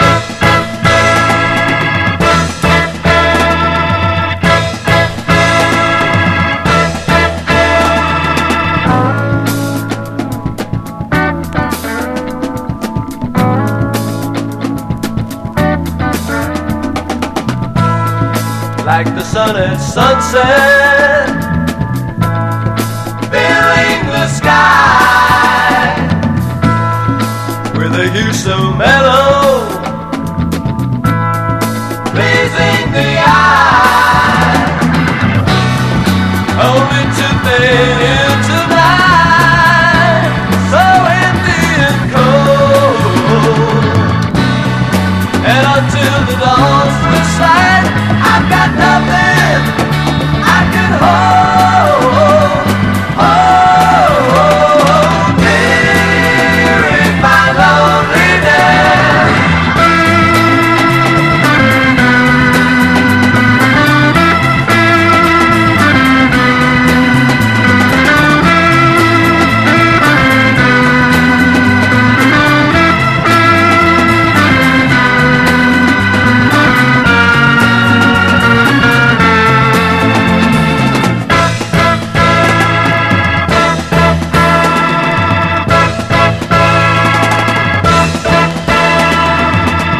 SOUL / SOUL / 70'S～ / DISCO / BLUE EYED SOUL